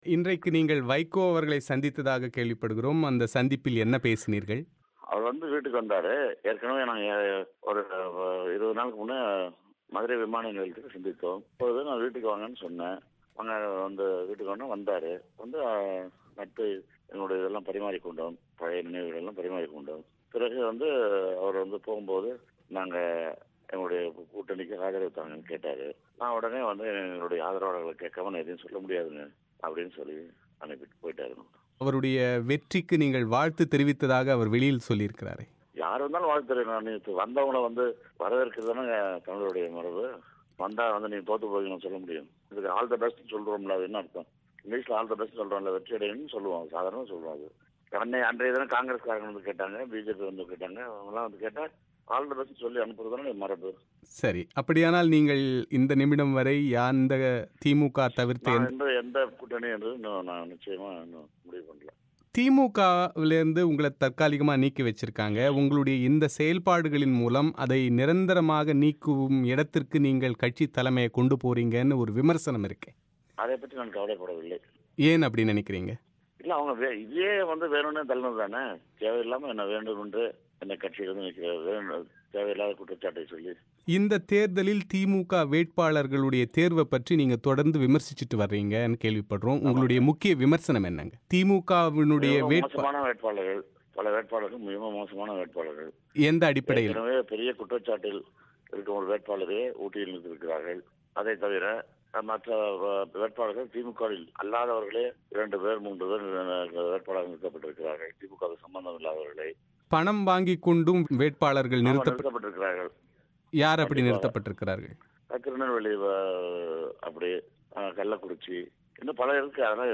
திமுக தலைவர் மு கருணாநிதியை வெறும் டம்மியாக வைத்துக்கொண்டு மு க ஸ்டாலின் தான் உண்மையில் கட்சியை நடத்திக் கொண்டிருக்கிறார் என்றும் அழகிரி பிபிசி தமிழோசைக்கு அளித்த செவ்வியில் தெரிவித்தார்.